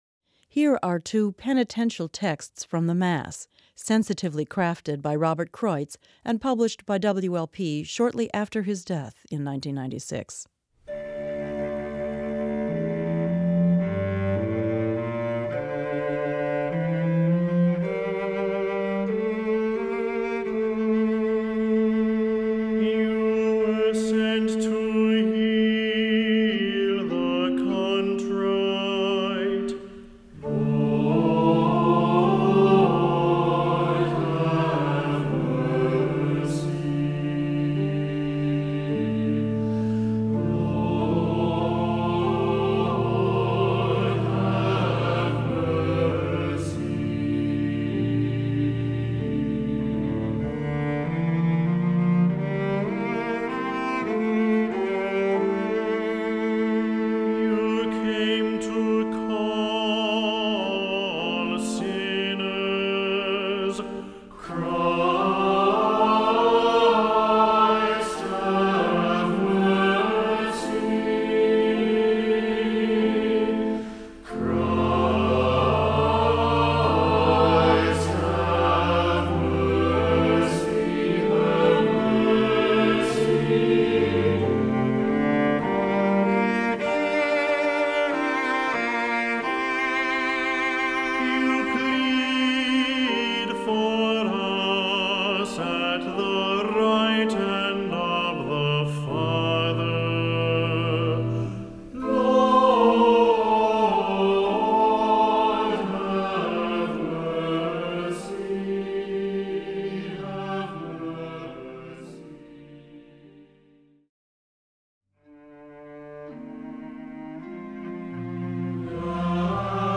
Voicing: Cantor,Assembly